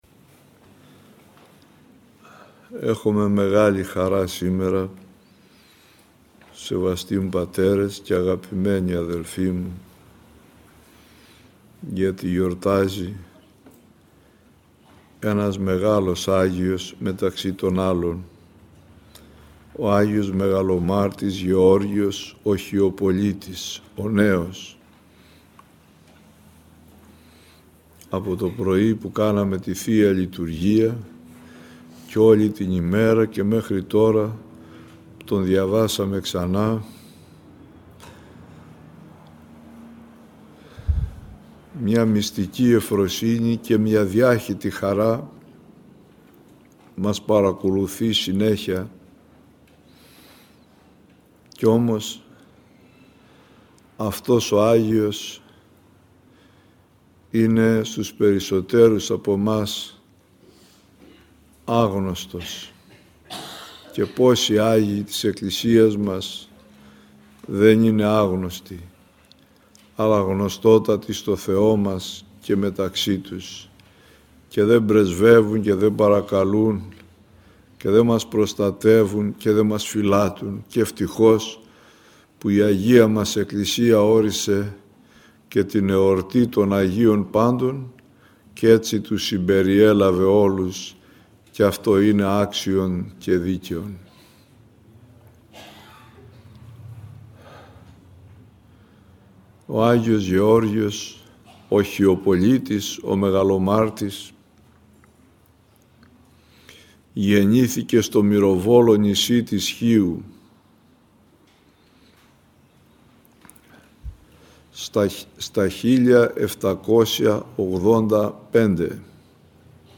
Η εν λόγω ομιλία αναμεταδόθηκε από τον ραδιοσταθμό της Πειραϊκής Εκκλησίας.